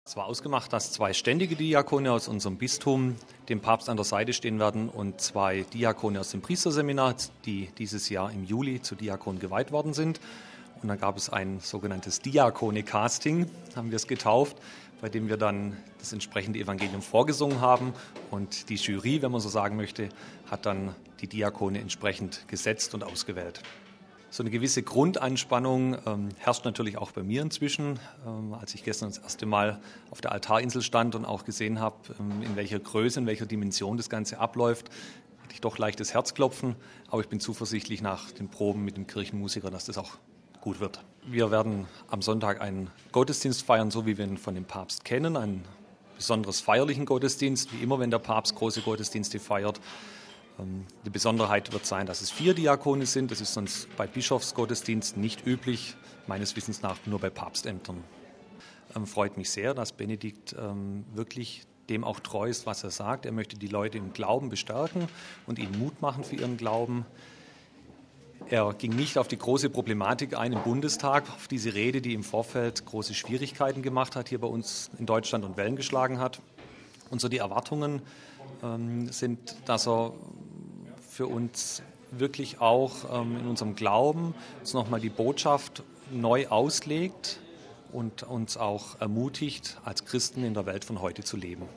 Er war in diesen Tagen stark in die Proben für die Messe eingebunden. Er sei schon etwas aufgeregt, verriet er vor dem Gottesdienst im Gespräch mit Radio Vatikan: